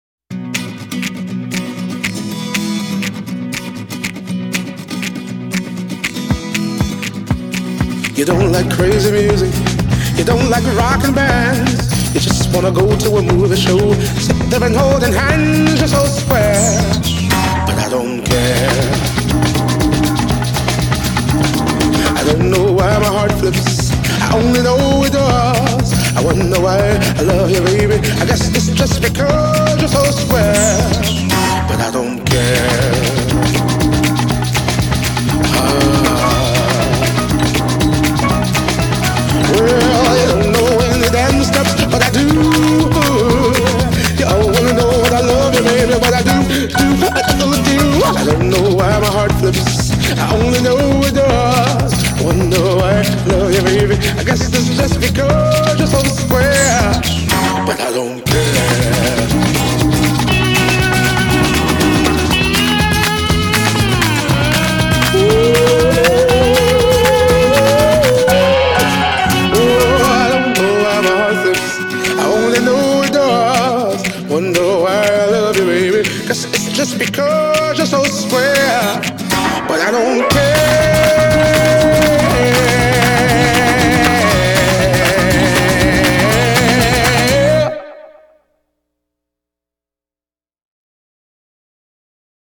is a U.S. hip hop, funk, soul, and R&B musician.